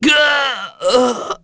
Esker-Vox_Dead.wav